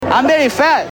Category: Television   Right: Personal
Tags: Guillermo Guillermo diaz Guillermo celebrity interview Guillermo from Kimmel Guillermo on Jimmy Kimmel show